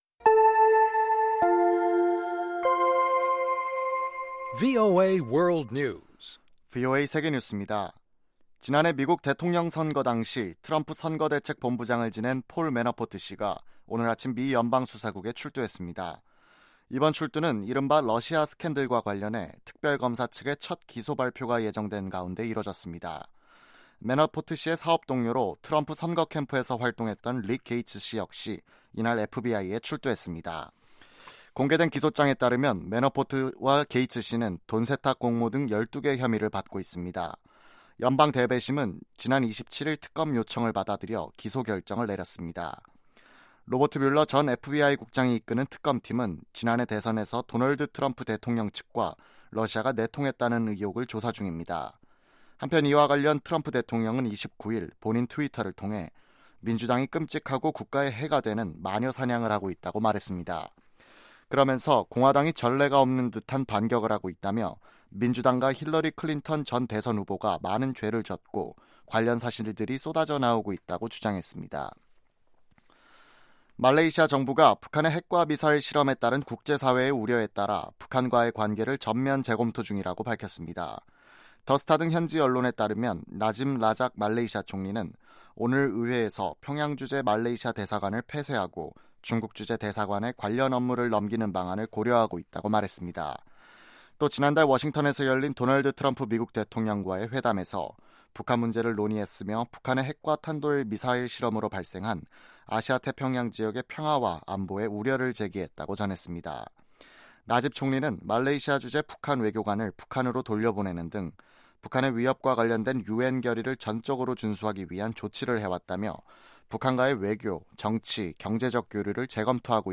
VOA 한국어 방송의 간판 뉴스 프로그램 '뉴스 투데이' 3부입니다. 한반도 시간 매일 오후 11:00 부터 자정 까지, 평양시 오후 10:30 부터 11:30 까지 방송됩니다.